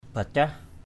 /ba-caɦ/ 1. (đg.) sụp, đổ vỡ. collapse, broken. ataong daop o bacah a_t” _d<P o% bcH phá không vỡ thành, không hạ được thành. 2.